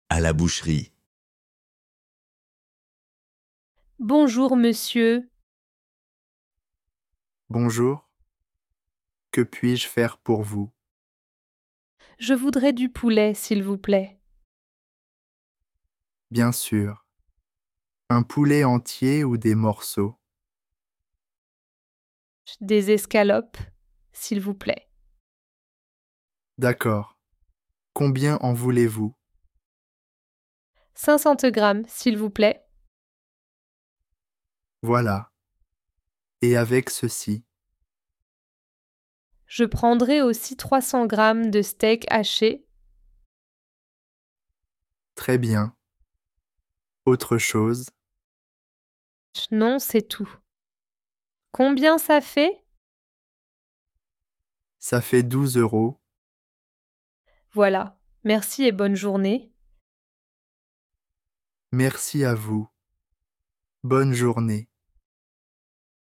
Dialogue en français à la boucherie A2 pour débutants
Dialogue FLE
Dialogue-en-francais-a-la-boucherie-A2.mp3